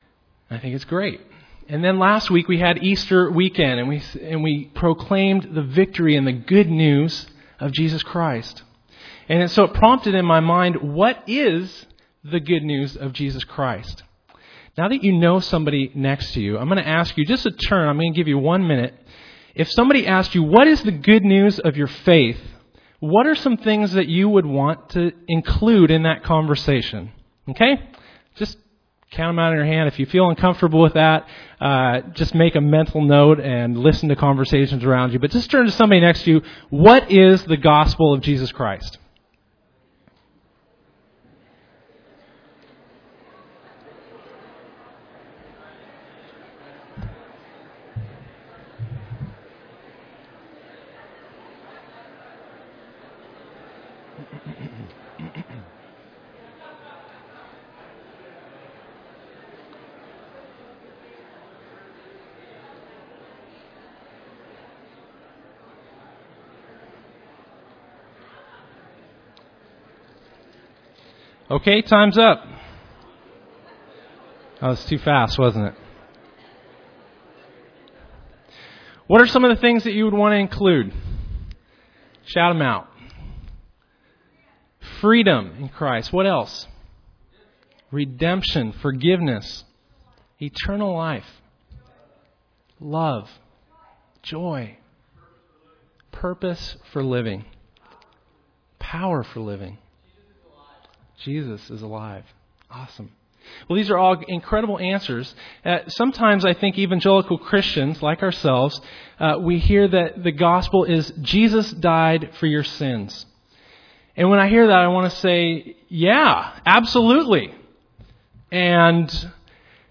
Preacher: Guest Preacher | Series: General Hebrews 1…